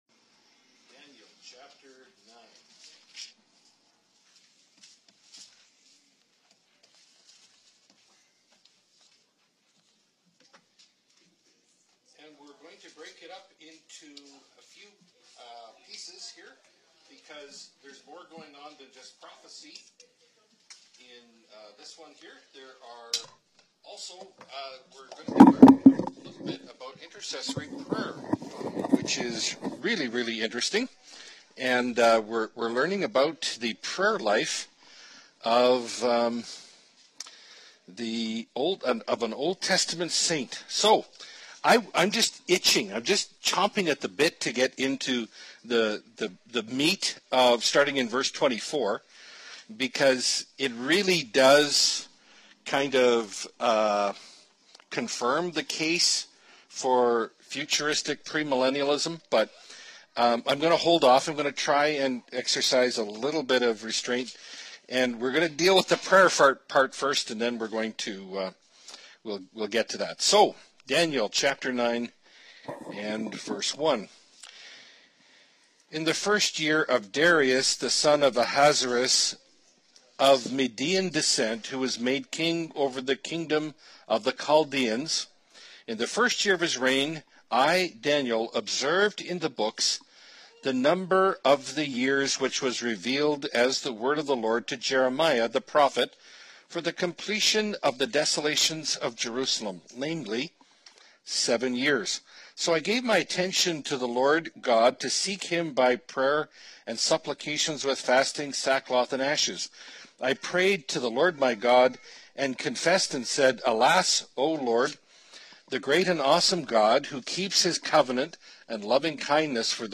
Bible Study – Daniel 9 – Part 1 of 3 (2017)